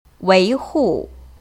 维护[ wéihù ]지키다